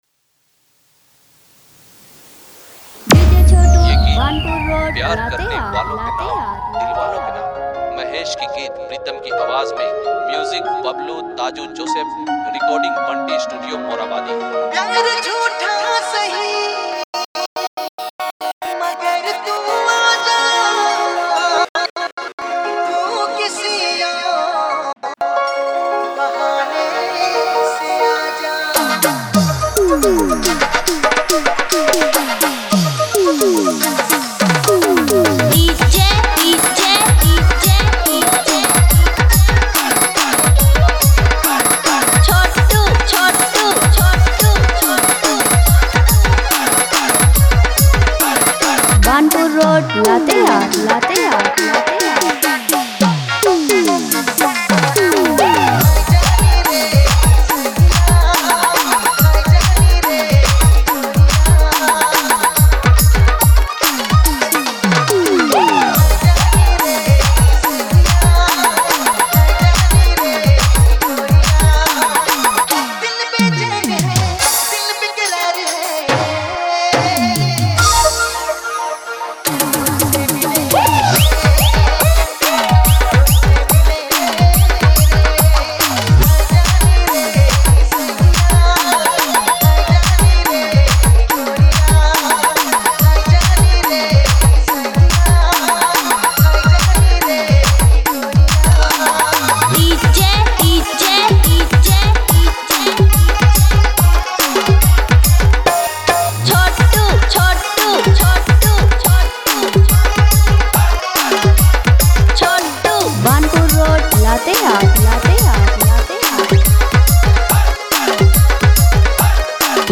soulful vibes